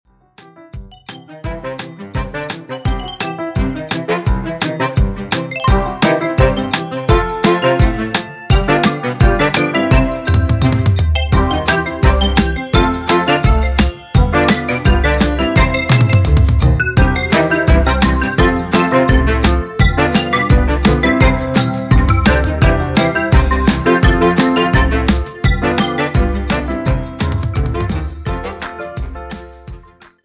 • Type : Instrumental
• Bpm : Allegretto
• Genre : Folk/ Country